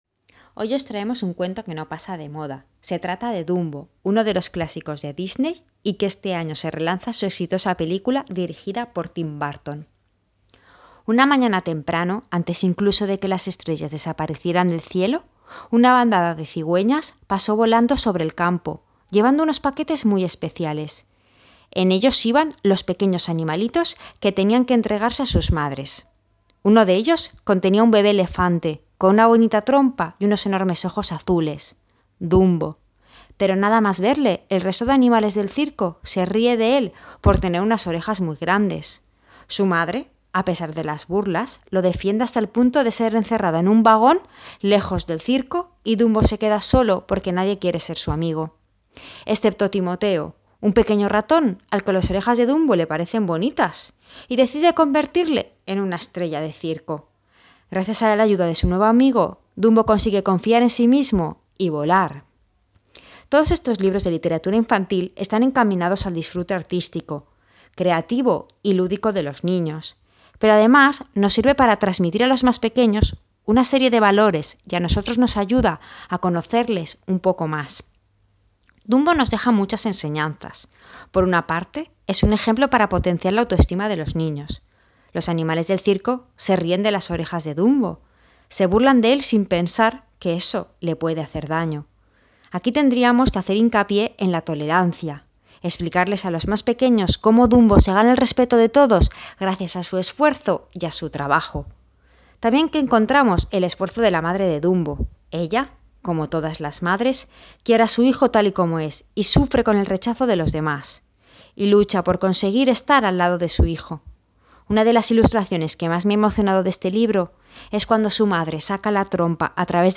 Audio reseña: Dumbo